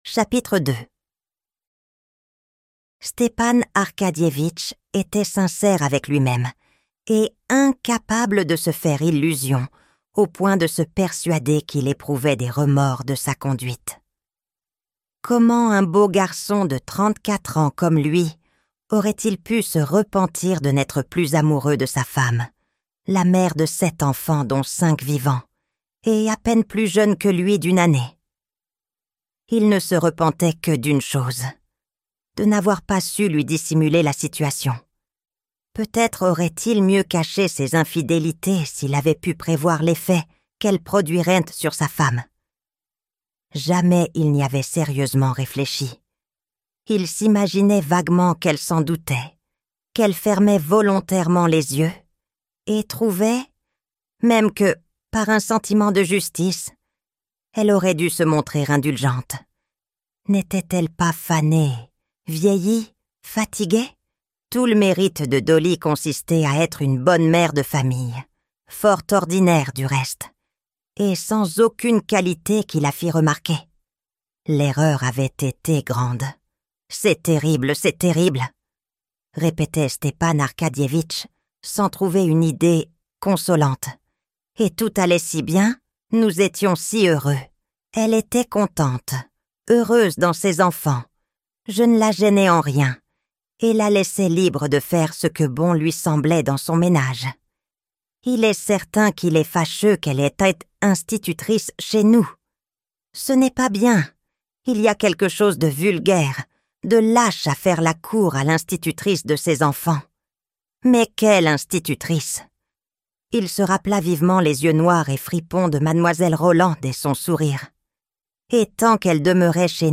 Anna Karenina - Livre Audio
Extrait gratuit - Anna Karenina - Livre Audio de Léo Tolstoï, Livres audio en français